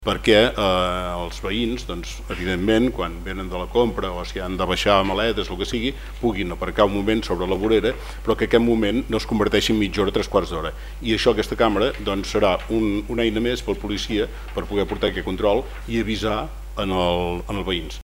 Ho explicava en el darrer ple el regidor d’Obra Pública, Lluís Ros.